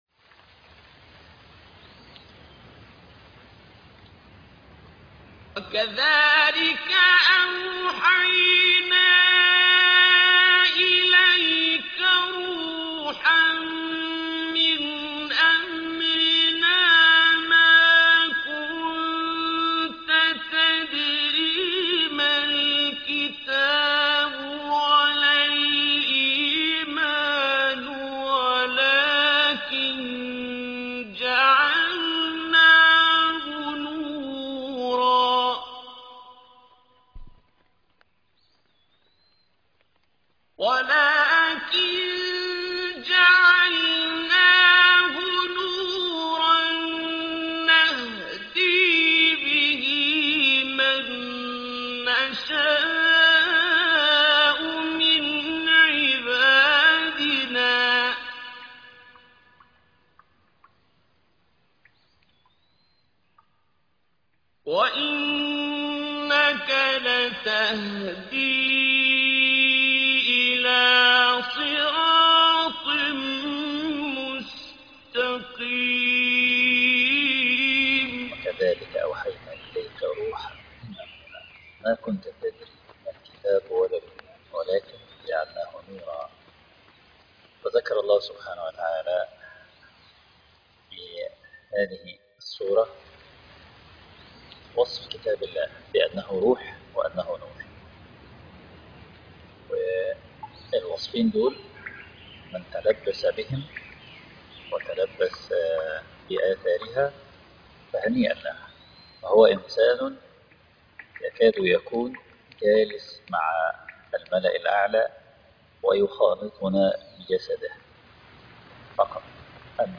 عنوان المادة وكذلك أوحينا إليك روحًا من أمرنا _ الجزء الخامس والعشرون _ قرأ الإمام